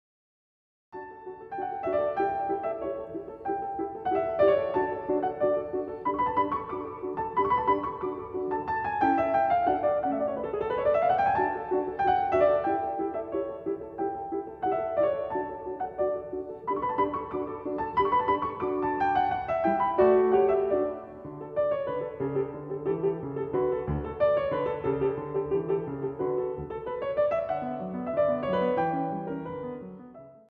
short pieces for the piano